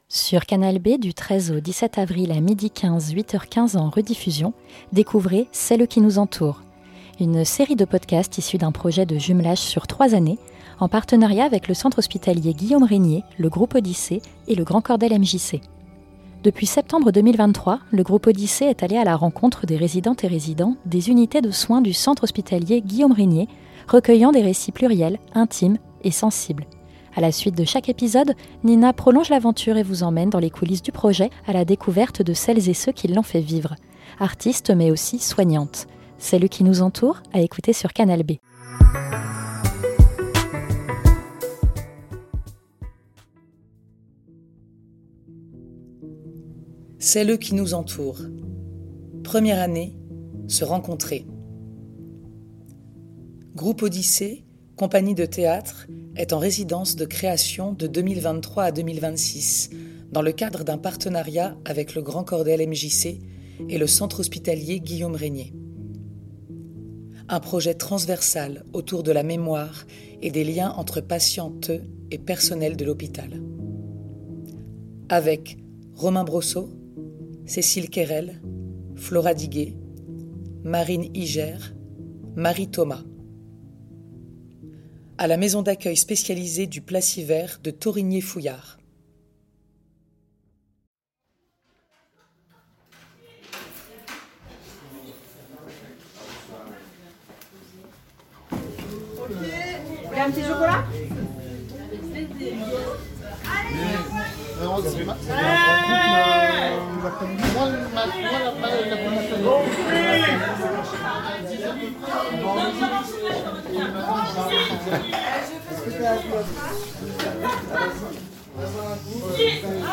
Restitution sonore
Interview A la suite de chaque épisode